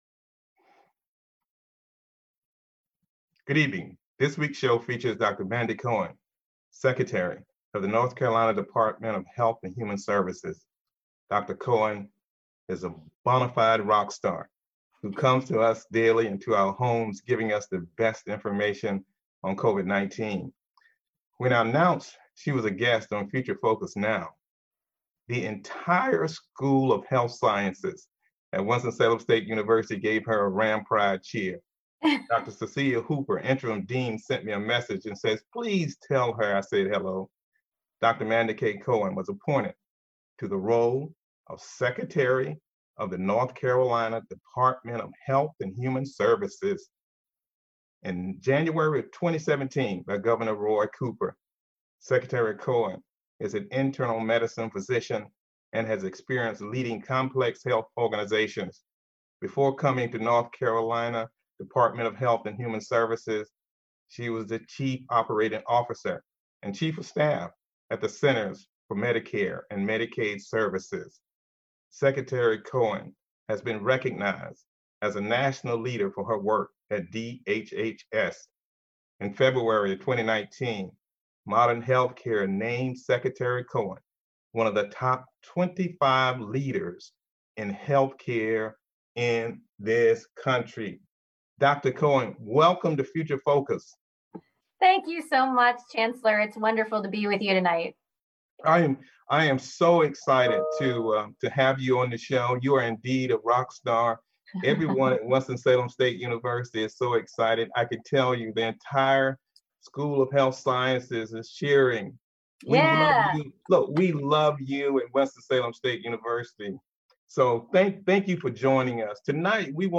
Future Focus is a one-hour public affairs talk show hosted by Winston-Salem State University's Chancellor Elwood Robinson.